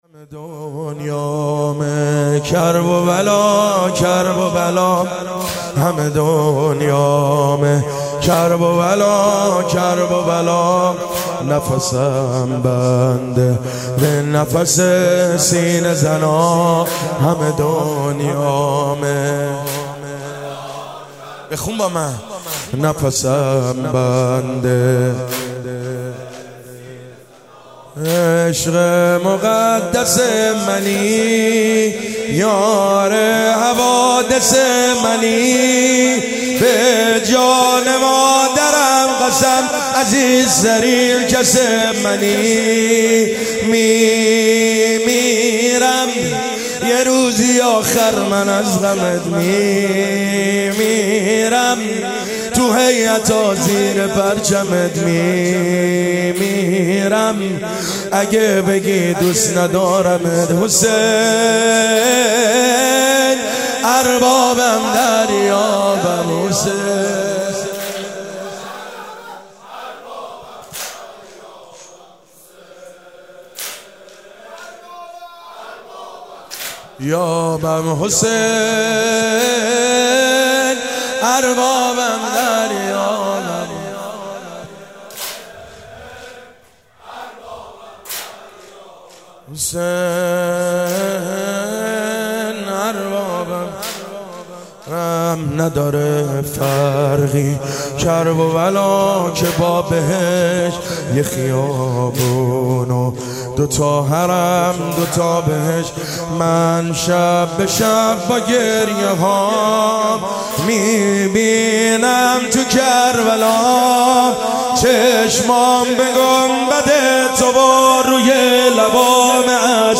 شب29صفر (6).mp3